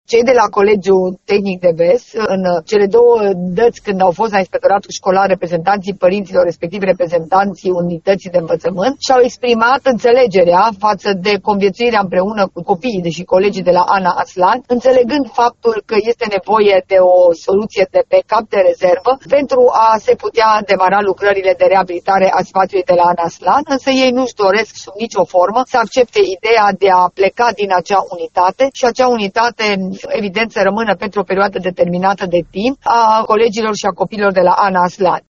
Șefa Inspectoratului Școlar Timiș, Aura Danielescu, spune că se caută soluții care să mulțumească ambele părți.